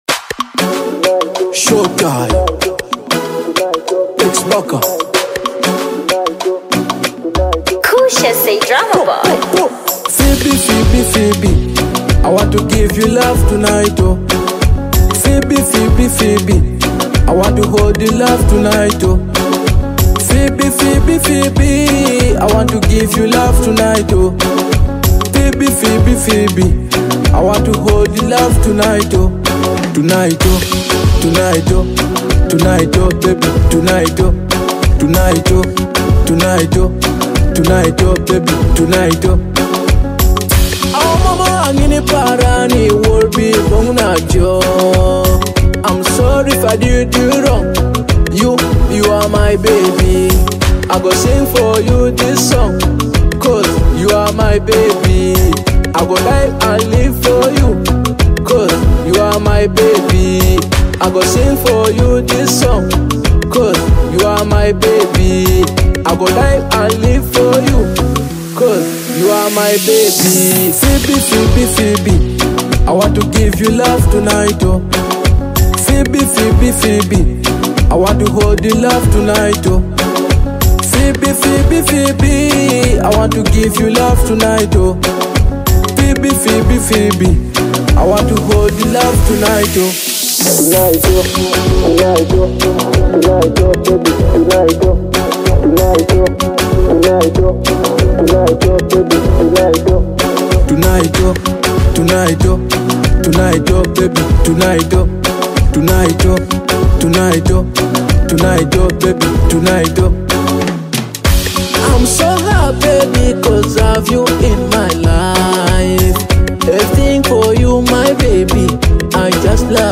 Teso and Amapiano music styles